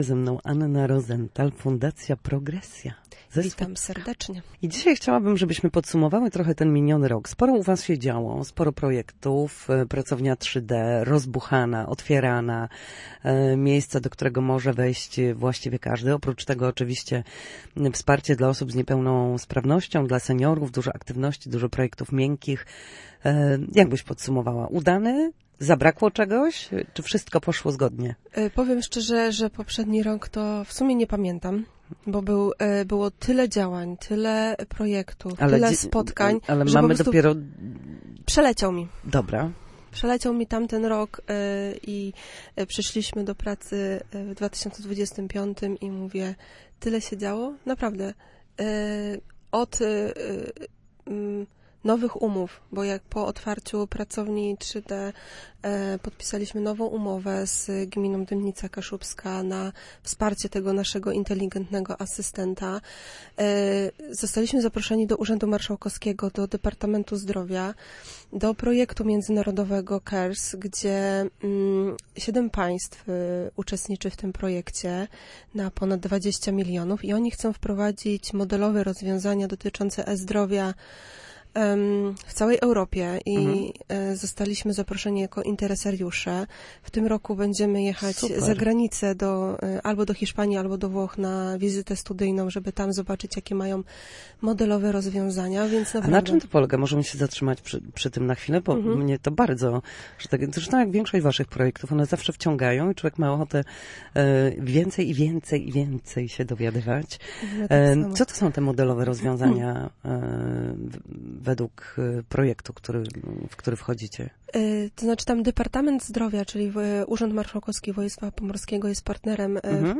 powiedziała na antenie Radia Gdańsk